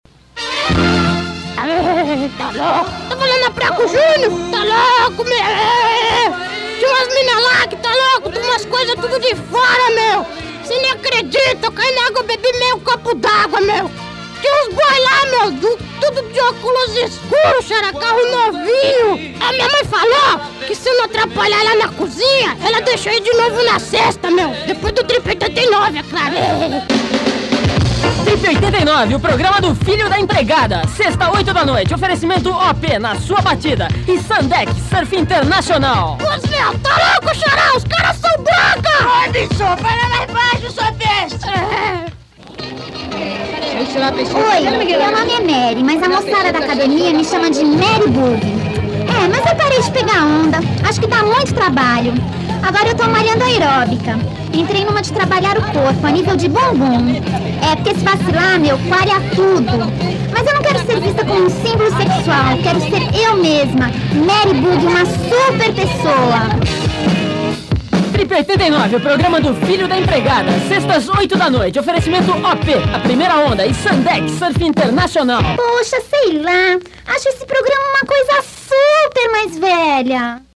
vinhetas históricas